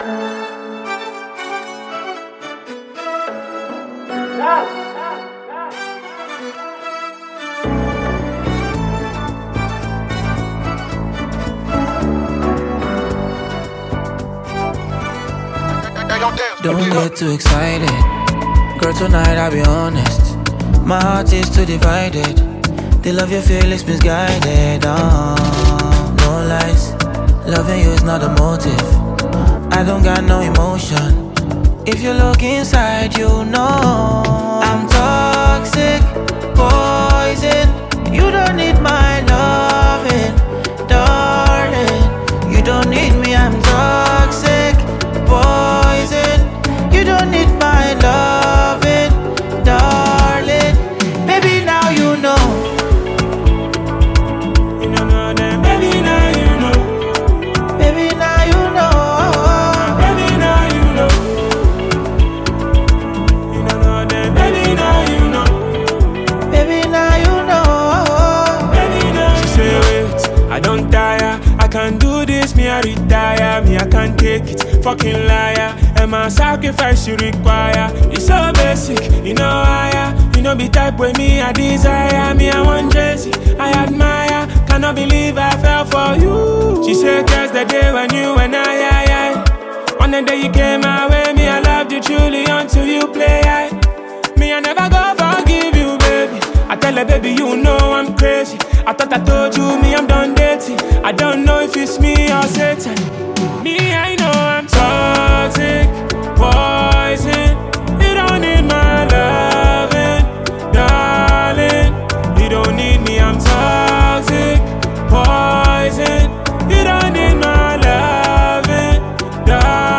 Ghanaian rapper
a catchy song